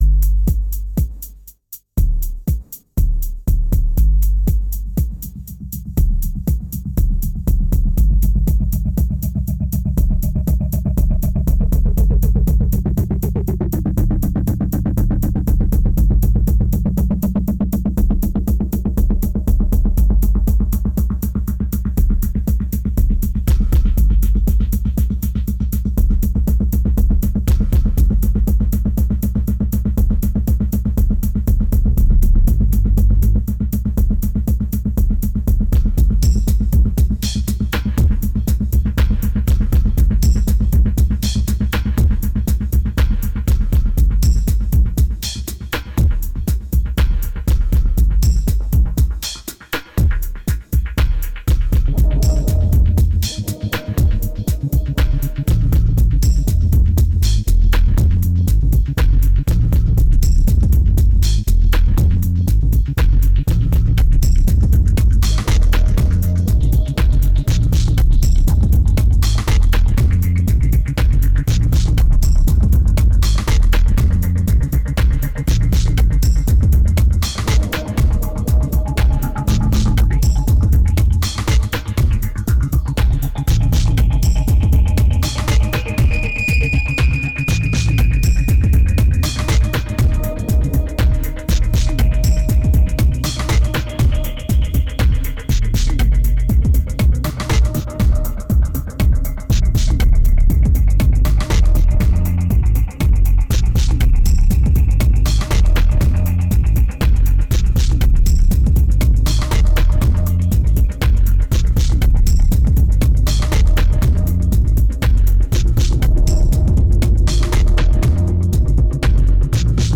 1946📈 - 0%🤔 - 120BPM🔊 - 2010-09-05📅 - -194🌟